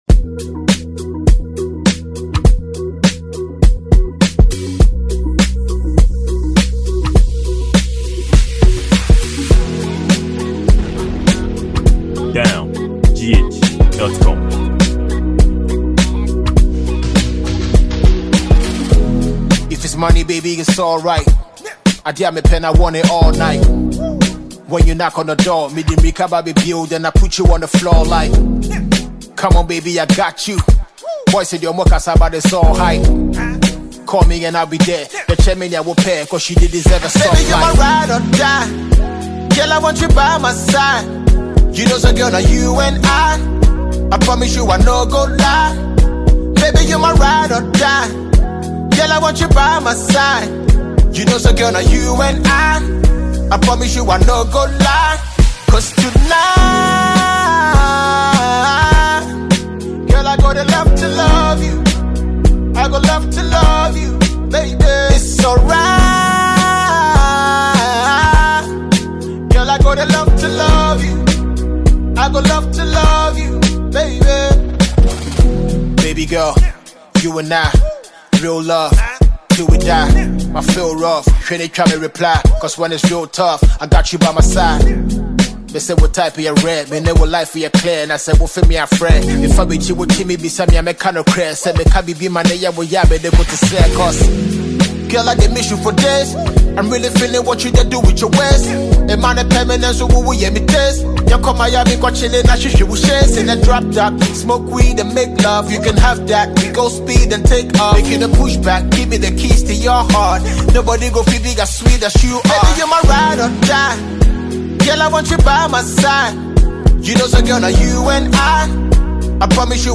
rap artist
Please feel this jaming banger below.